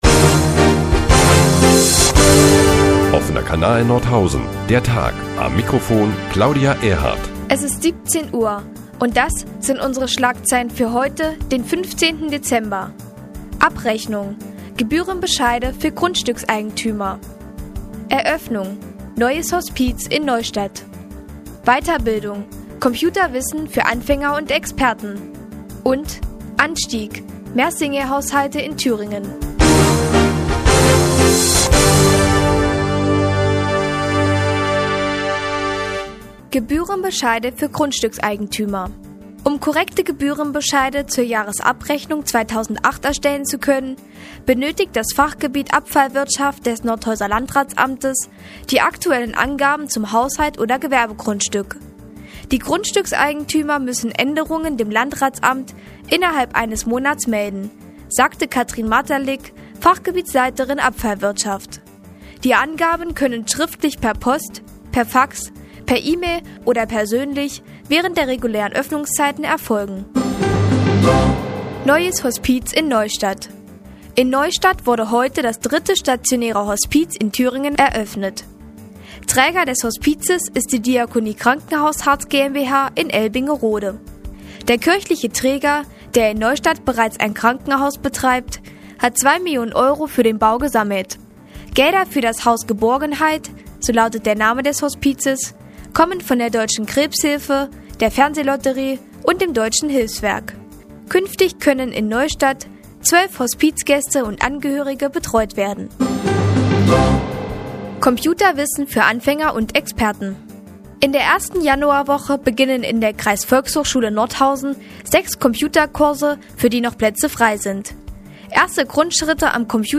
Die tägliche Nachrichtensendung des OKN ist nun auch in der nnz zu hören. Heute geht es unter anderem um die Eröffnung eines Hospizes in Neustadt und Gebührenbescheide für Grundstückseigentümer.